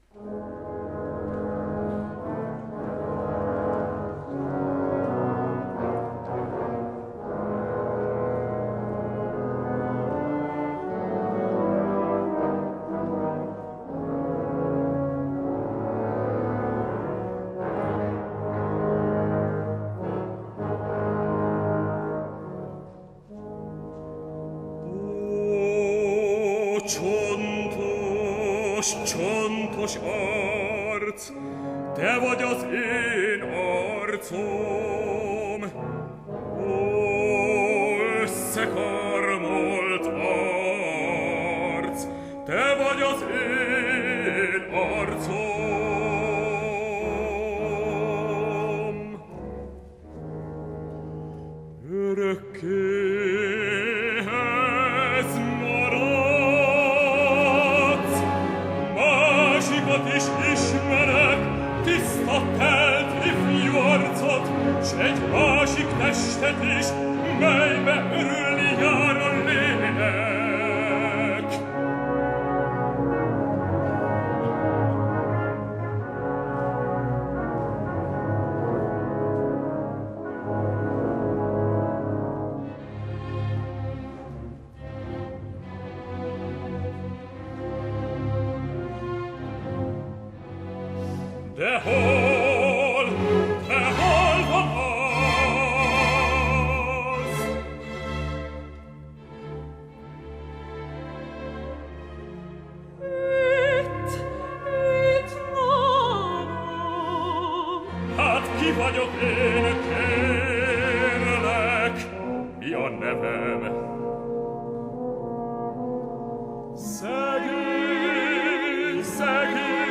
opera 2 felvonásban